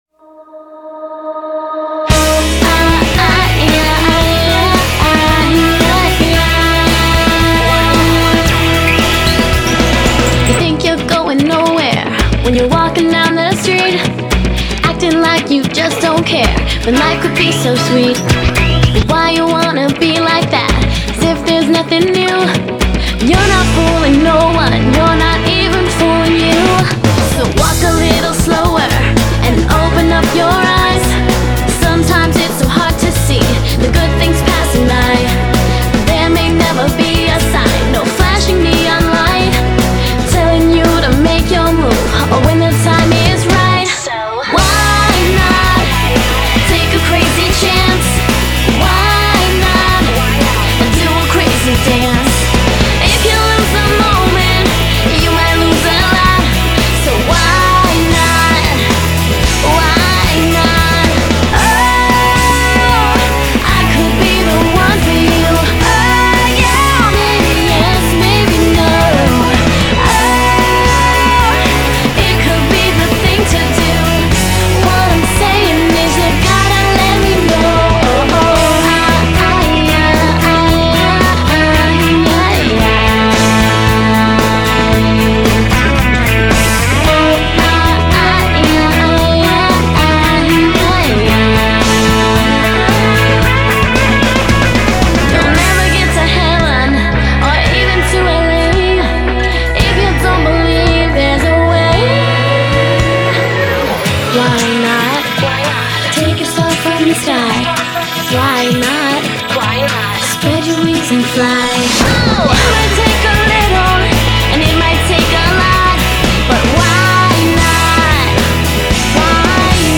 BPM56-113